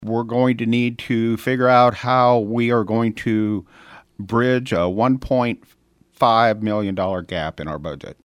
Newly elected mayor, Mike Ladehoff, stopped by the KFJB studio last week to preview what is going to be discussed.